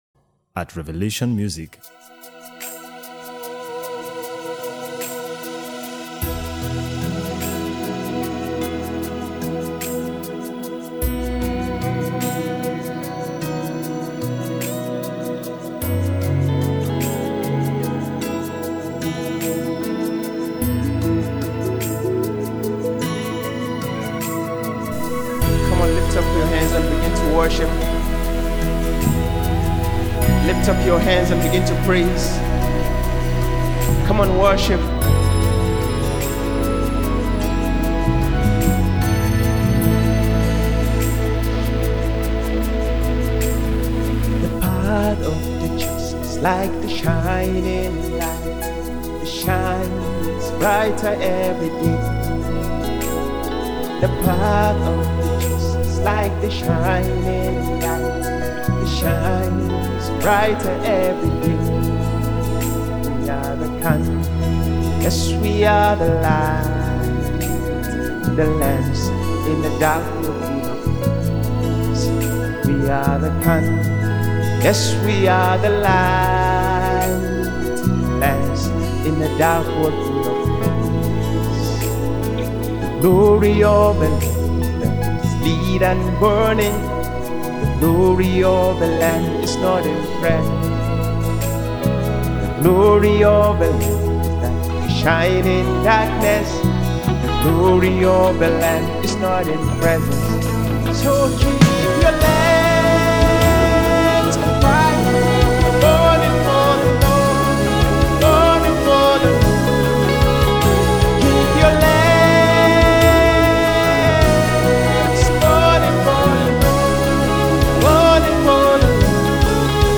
a revival anthem for this generation